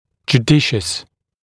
[ʤuː’dɪʃəs][джу:’дишэс]благоразумный, здравомыслящий, разумный